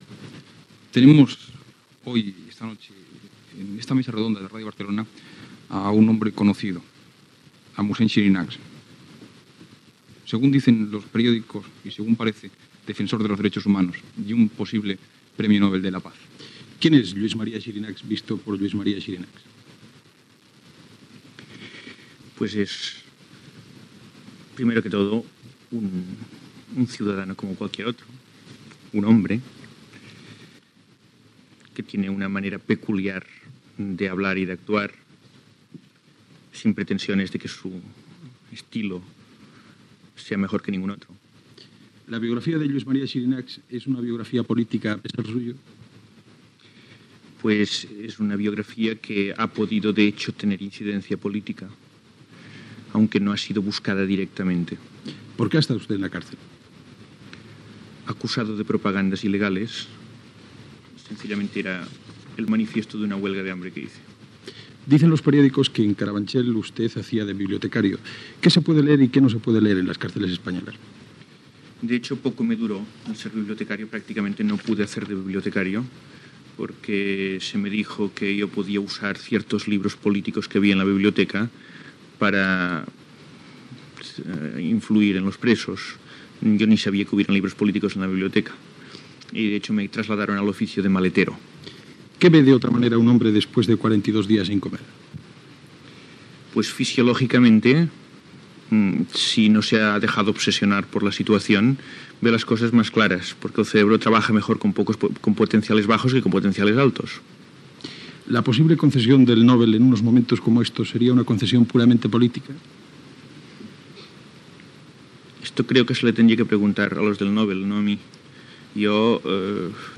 e7b510e949b4d086db9977cd6e69cf847652fd0c.mp3 Títol Cadena SER Emissora Ràdio Barcelona Cadena SER Titularitat Privada estatal Nom programa Hora 25 Descripció Entrevista a Lluís Maria Xirinachs dos dies després de ser posat en llibertat després d'estar empresonat a Carabanchel. Gènere radiofònic Informatiu